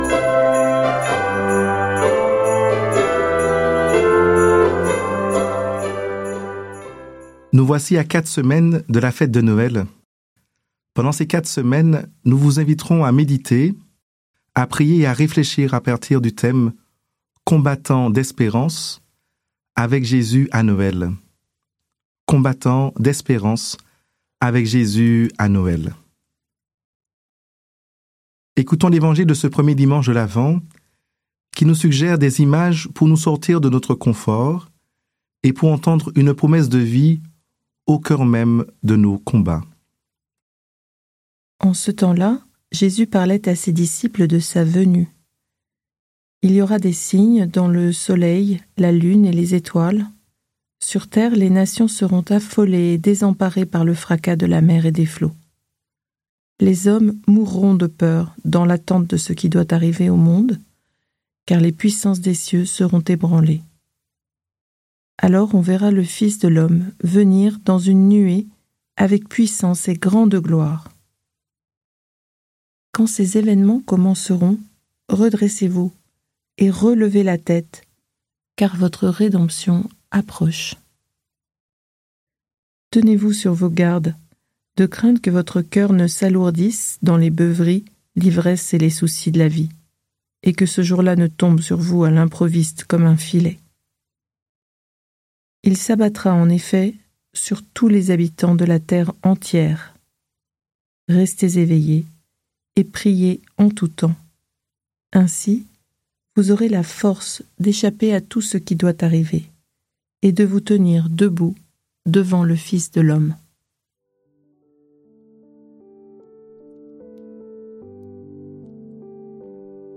Prière audio avec l'évangile du jour - Prie en Chemin